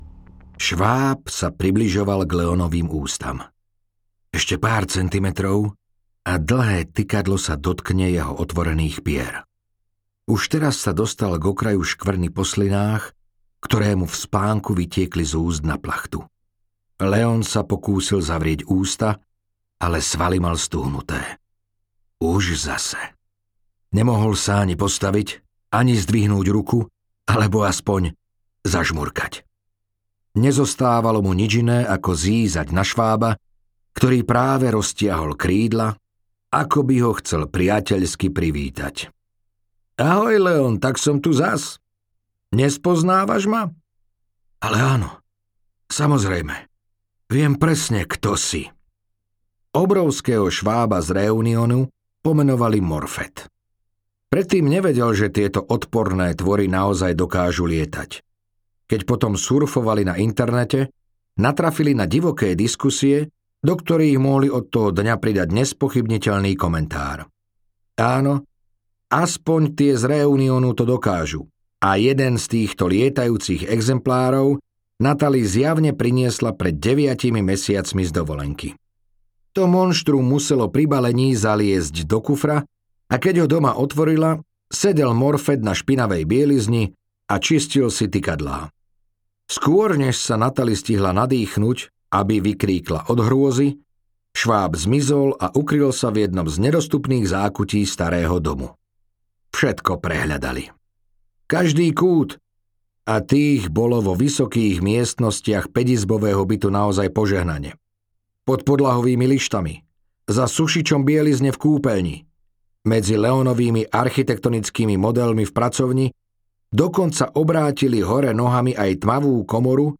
Námesačný audiokniha
Ukázka z knihy